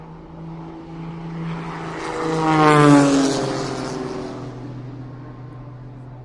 螺旋桨飞机 " 一架螺旋桨飞机经过
描述：螺旋桨飞机。需要去嘶嘶声